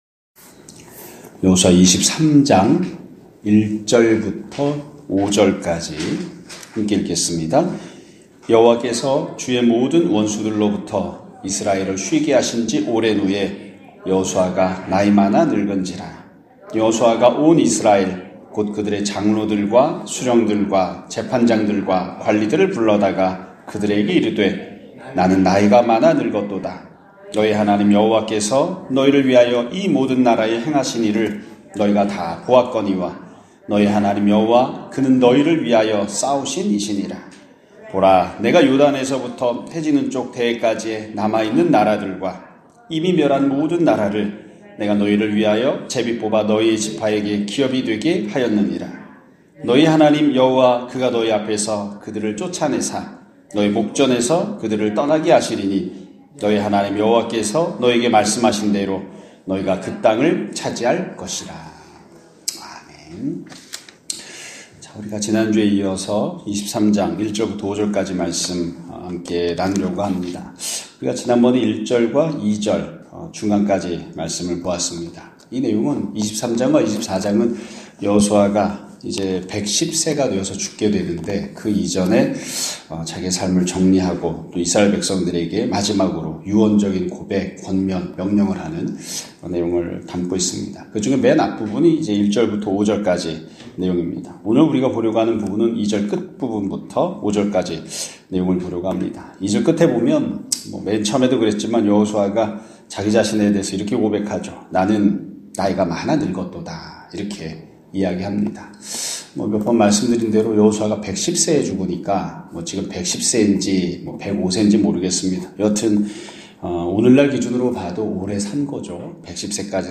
2025년 2월 17일(월요일) <아침예배> 설교입니다.